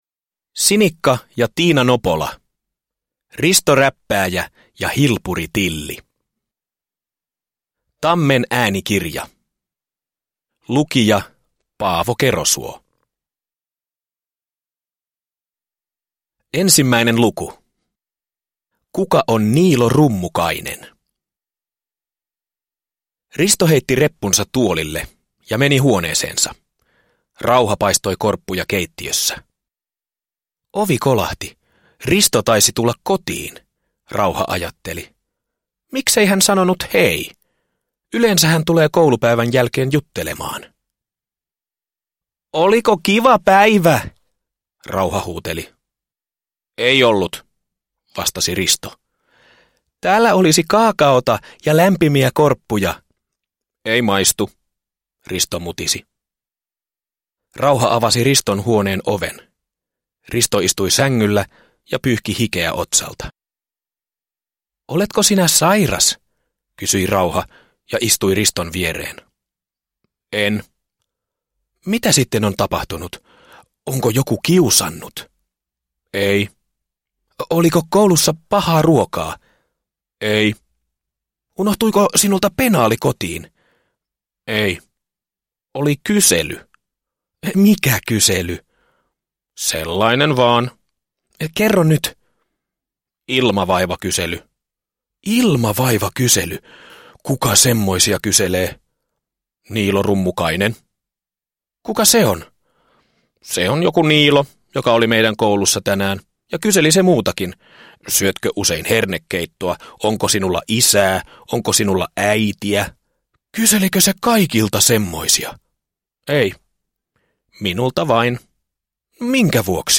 Risto Räppääjä ja Hilpuri Tilli – Ljudbok